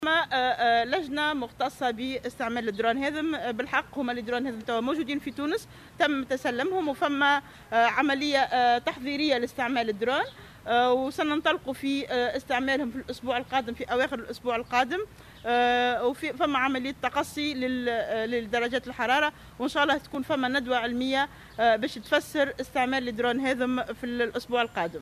وأضافت خلال ندوة صحفية عقدتها الوزارة اليوم لتقييم تطور الوضع الوبائي لجائحة كورونا في تونس، أنه سيتم عقد ندوة علمية لتفسير كيفية استعمال "الدرون".